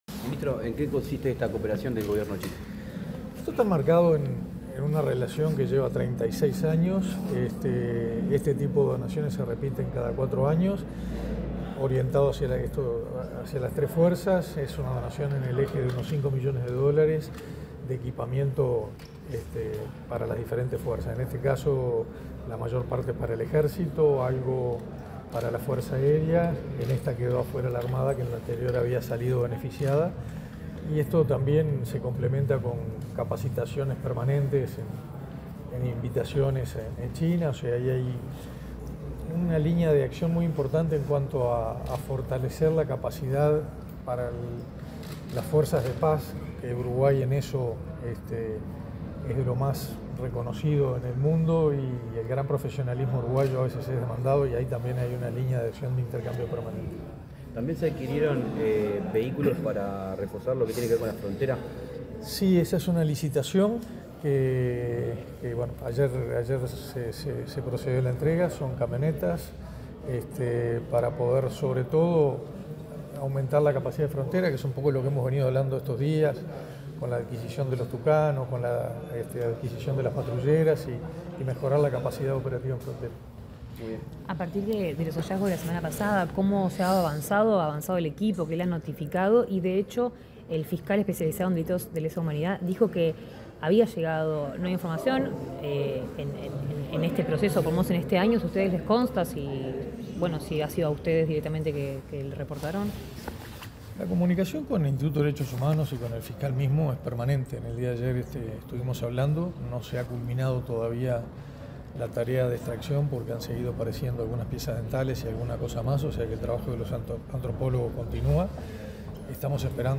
Declaraciones del ministro de Defensa Nacional, Armando Castaingdebat
Declaraciones del ministro de Defensa Nacional, Armando Castaingdebat 09/08/2024 Compartir Facebook X Copiar enlace WhatsApp LinkedIn Luego de la firma de un acuerdo para recibir una donación de la República Popular China, el ministro de Defensa Nacional, Armando Castaingdebat, realizó declaraciones a la prensa.